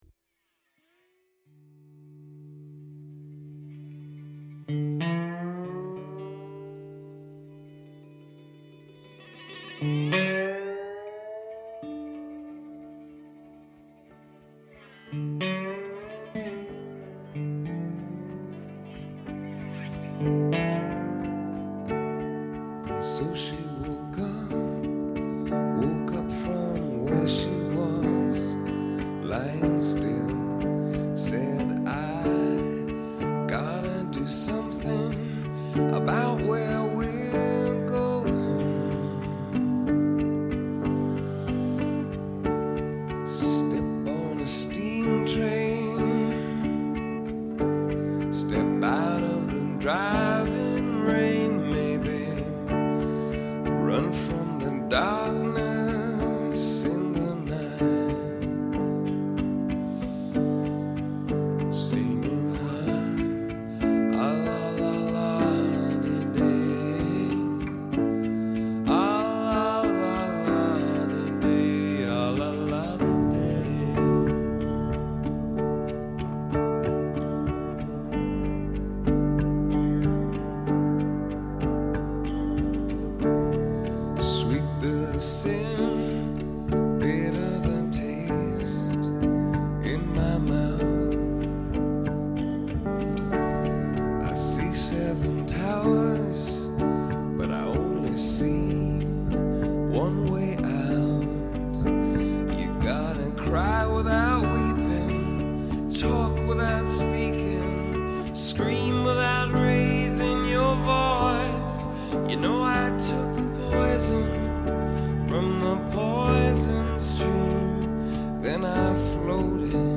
but this poetic rock album remains their masterpiece.